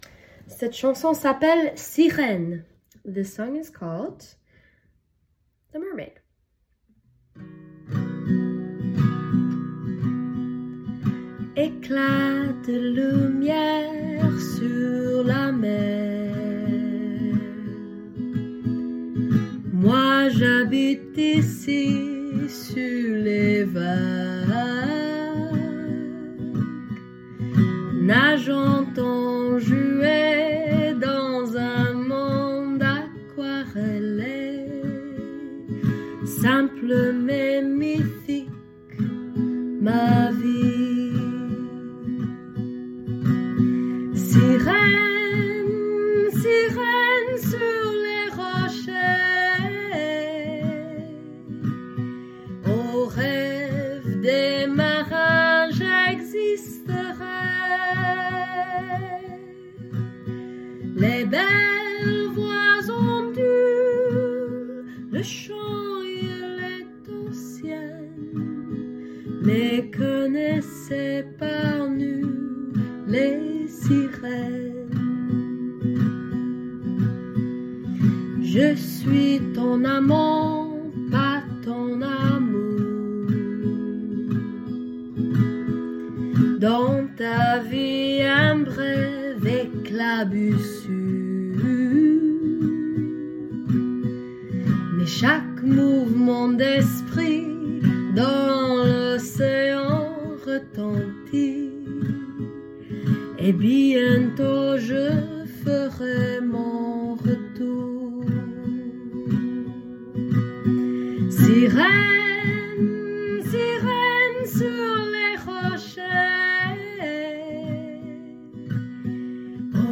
As others said, this has a lovely lilt, and I'm also enjoying the slightly old-timey sound of these particular chord and strumming patterns.
Gorgeous melody and vocals!
Beautiful singing.. this has lovely lilt to it..